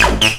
tekTTE63026acid-A.wav